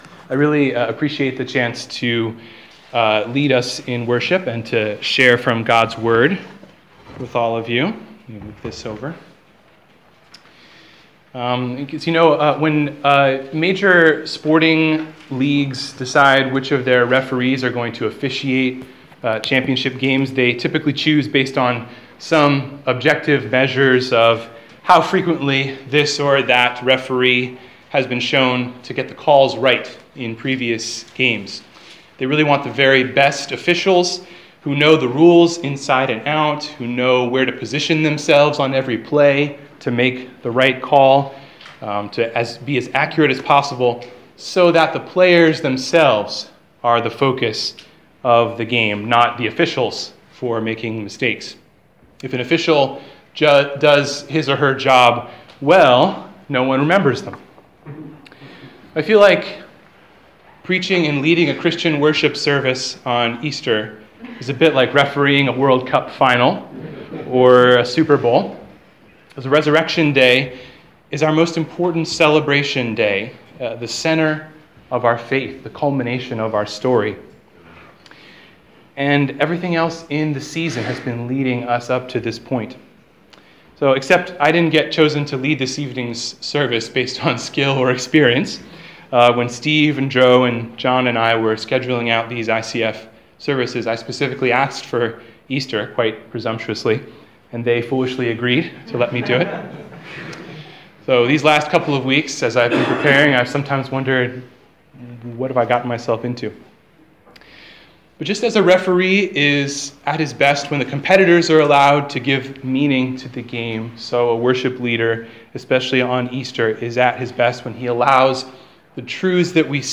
Sermon: The First Fruits of Resurrection